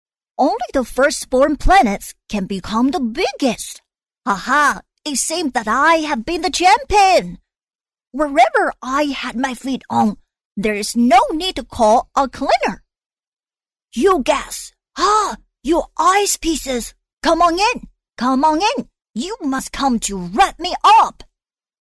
女158-英语配音角色【沸羊羊风】
女158-中英双语 可爱
女158-英语配音角色【沸羊羊风】.mp3